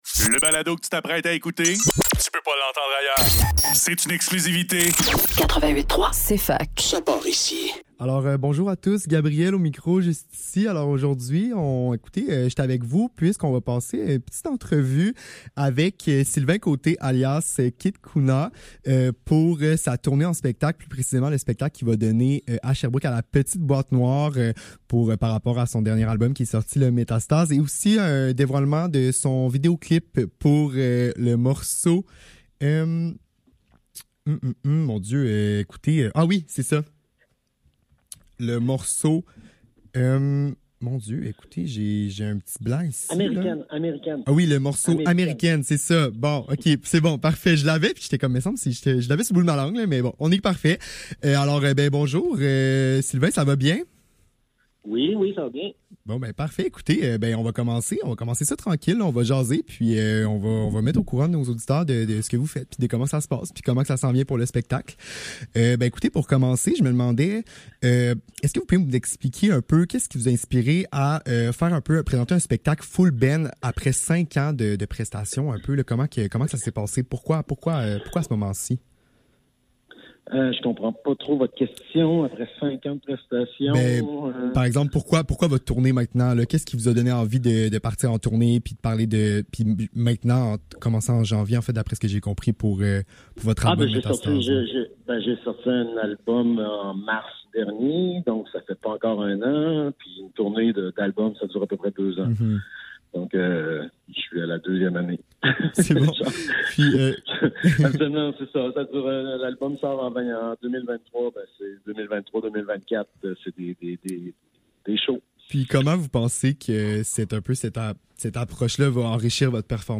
Faudrait que tout l'monde en parle - Entrevue avec Keith Kouna - 8 février 2024